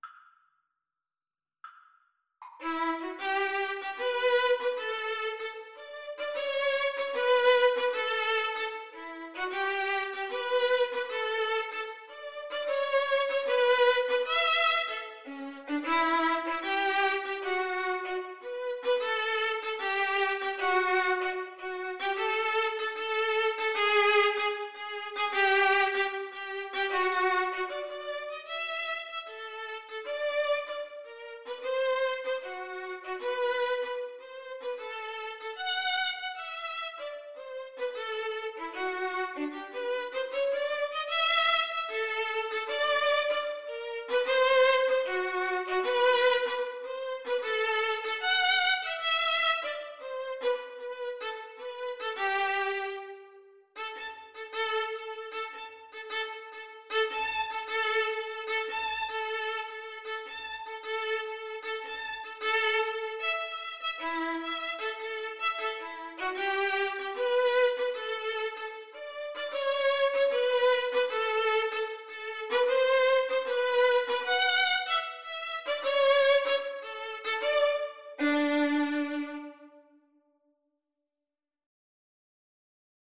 Classical (View more Classical Violin-Cello Duet Music)